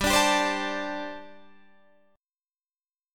Listen to Gsus2sus4 strummed